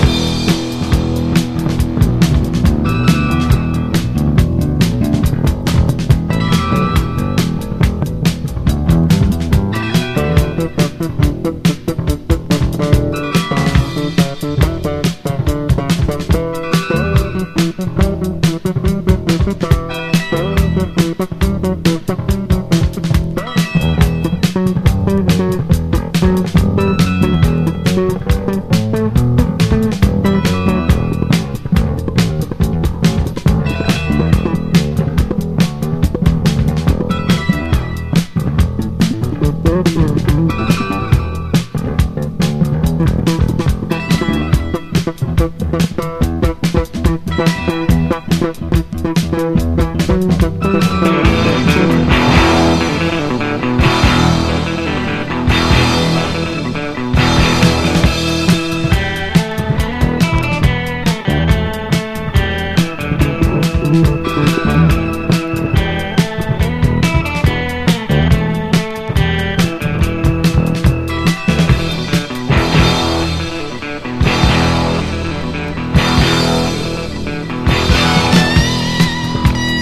ROCK / 70'S / PROGRESSIVE ROCK / FOLK ROCK / FUNKY ROCK
フォーク、ファンキーロック、エスノ、ボブ・ディラン...なんでもありのスウェーデン・フォークロック！